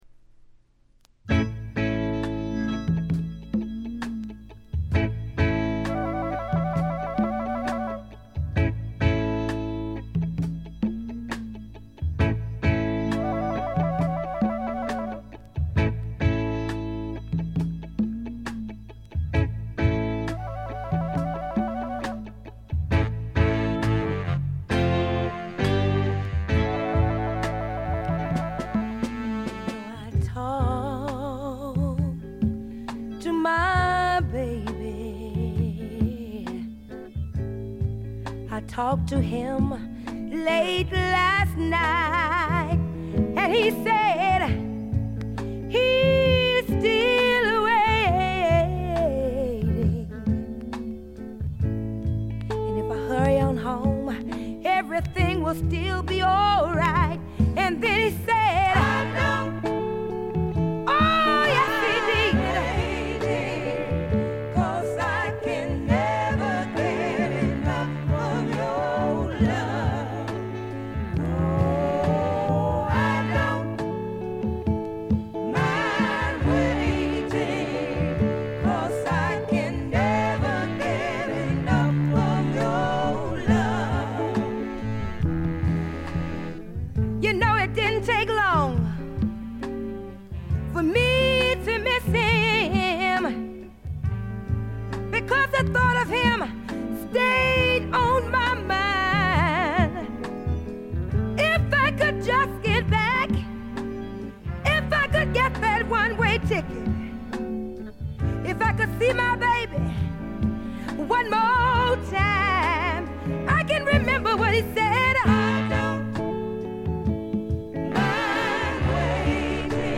メンフィス産の男女4人組のソウル・ヴォーカル・グループ。
試聴曲は現品からの取り込み音源です。